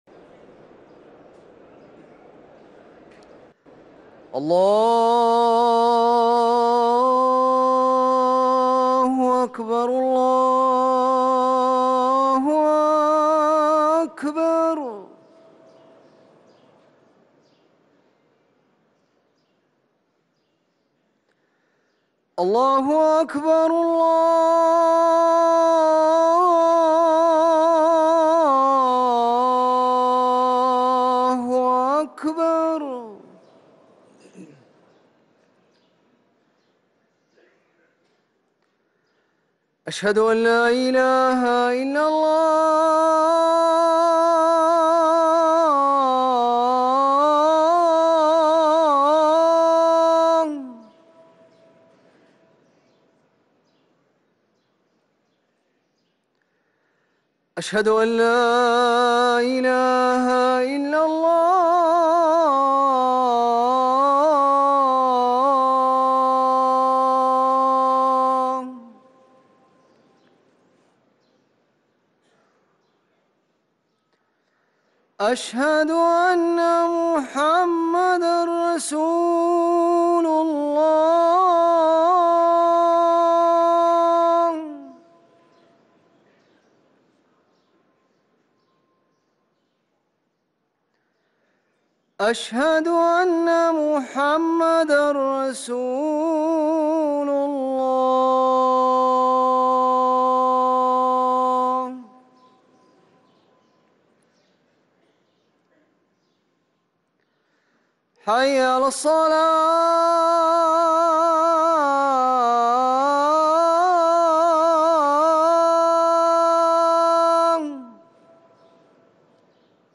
أذان العصر